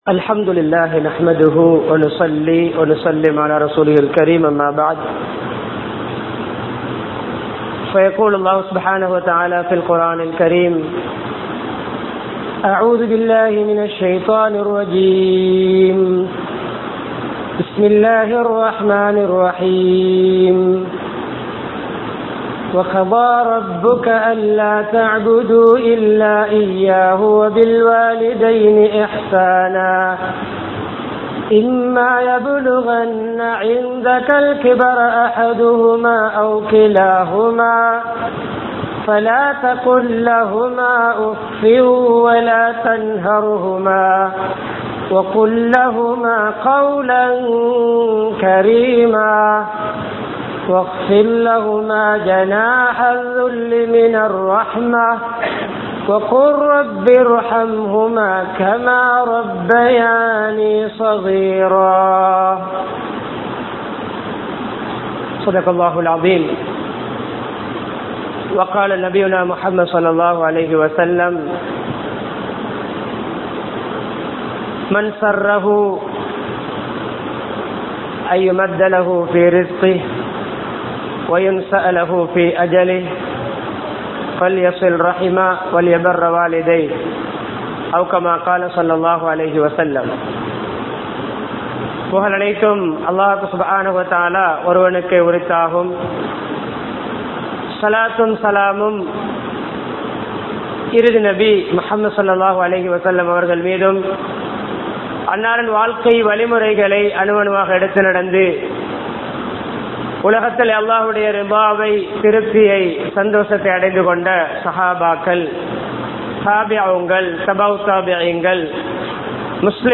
பெற்றோர்களின் சிறப்புகள் | Audio Bayans | All Ceylon Muslim Youth Community | Addalaichenai